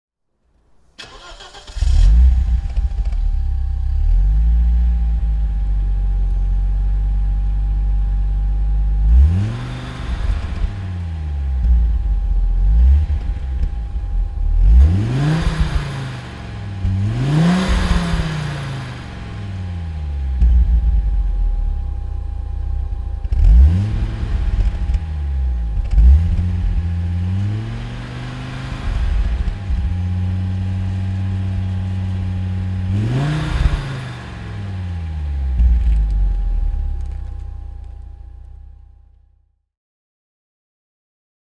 The 2.2-liter five-cylinder engine produced 315 hp, the car accelerated almost as fast as a Ferrari F355 costing twice as much and could not be left behind by a Porsche 993.
Audi Avant RS2 (1995) - Starten und Leerlauf
Audi_Avant_RS2_1995.mp3